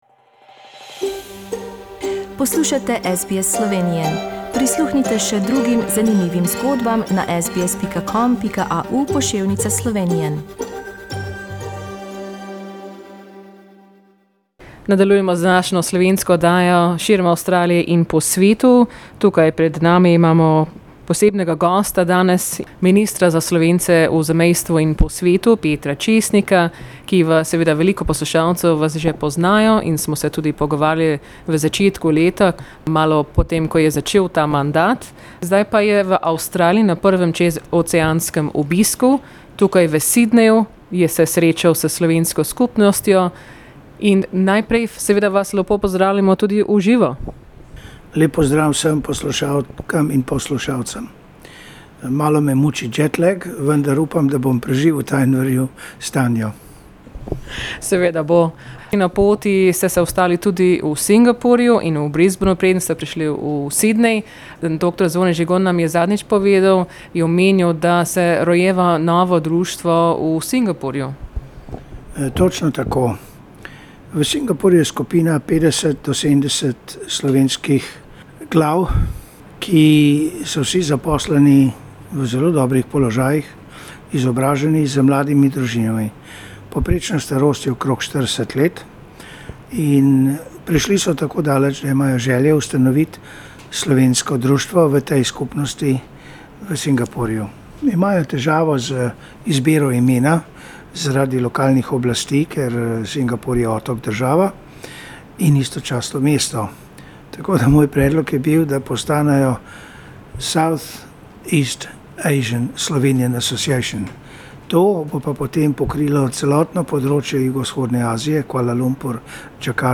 Minister Republike Slovenije za Slovence v zamejstvu in po svetu, Peter Česnik, je te dni na prvem čez oceanskem obisku v svojem mandatu, v Avstraliji. Pogovarjali smo se z njim ko je obiskal slovensko skupnost v Sydneyu.